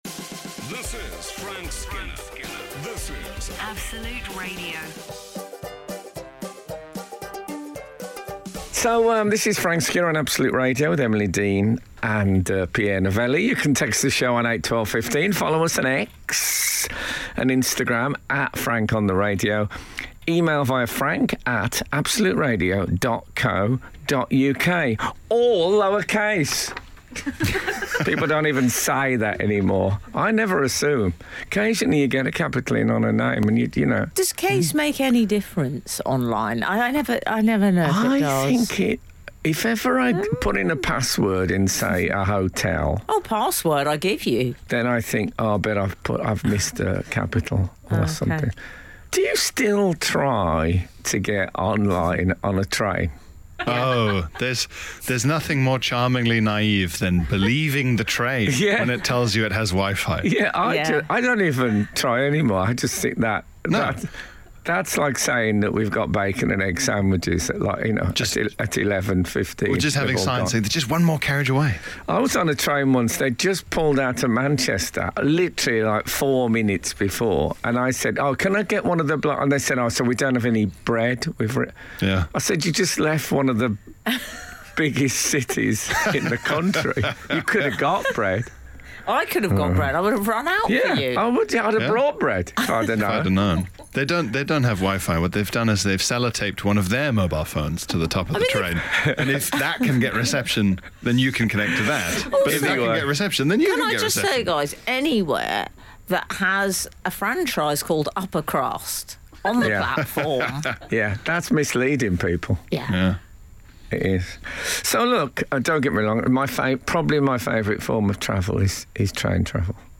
Mellow Frank Frank Off The Radio: The Frank Skinner Podcast Avalon Comedy 4.6 • 4.5K Ratings 🗓 13 January 2024 ⏱ 67 minutes 🔗 Recording | iTunes | RSS 🧾 Download transcript Summary Frank Skinner's on Absolute Radio every Saturday morning and you can enjoy the show's podcast right here.